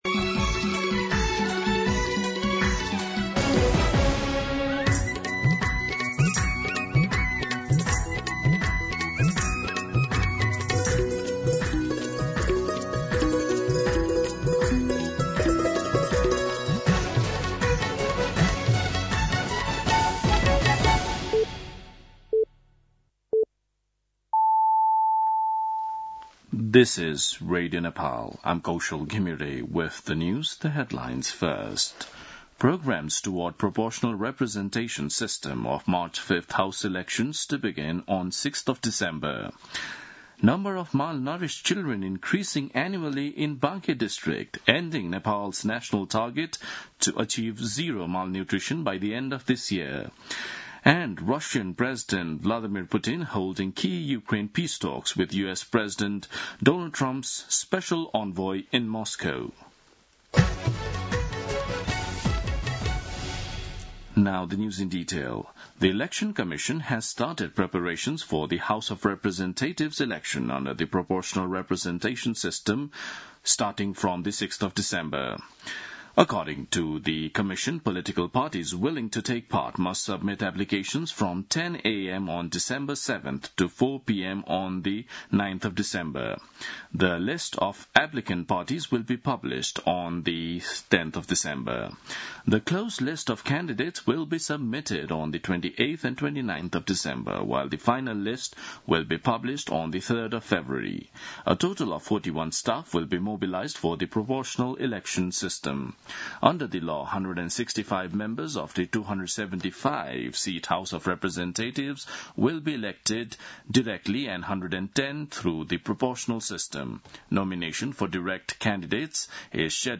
दिउँसो २ बजेको अङ्ग्रेजी समाचार : १६ मंसिर , २०८२
2-pm-English-News-8-16.mp3